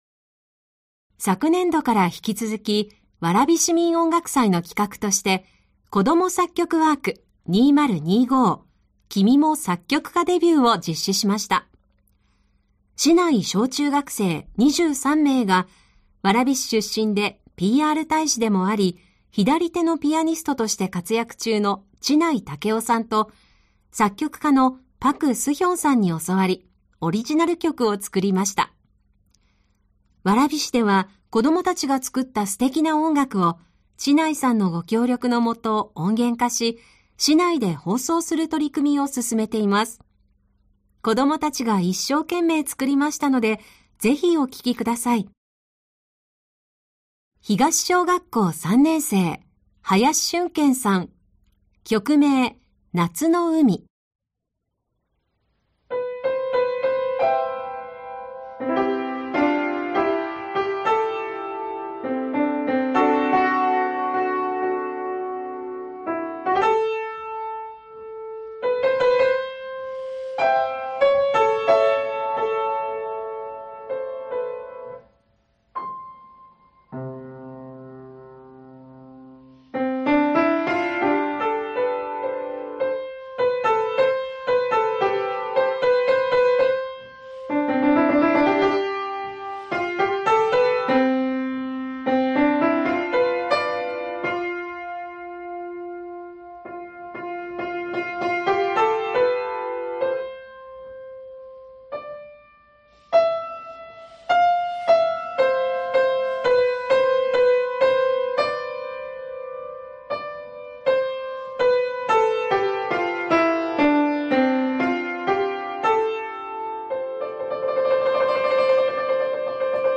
子どもたちが作った楽曲を音源ファイルから聴くことができます！